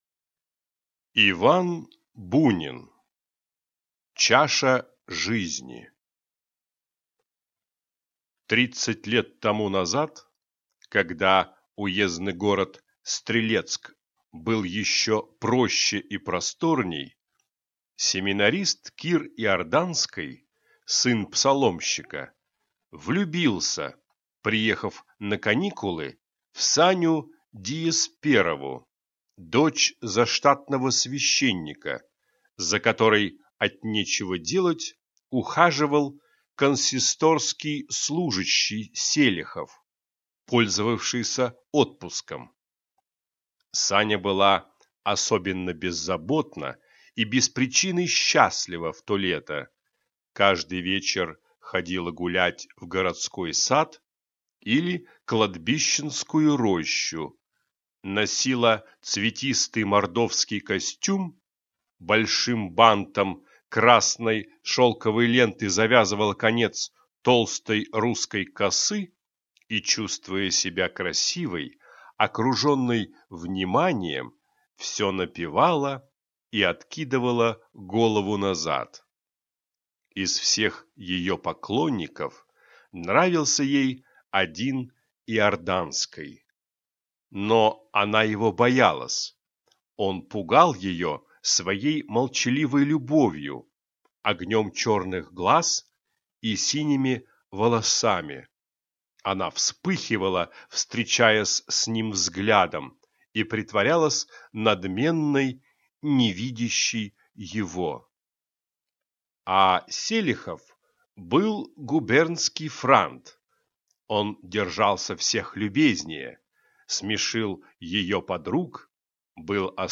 Аудиокнига Чаша жизни | Библиотека аудиокниг